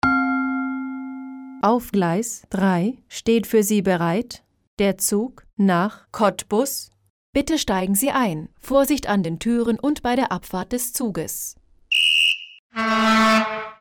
Lautsprecheransagen (Abfahrten)
• Gong (einfach)
• Abfahrtspfiff der Aufsicht mit Quittungston vom Signalhorn einer Diesellok